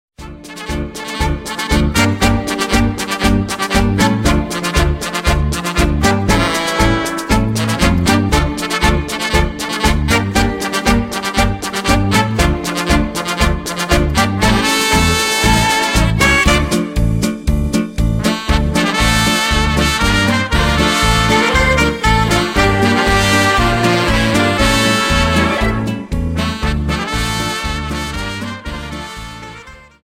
Paso Doble 60 Song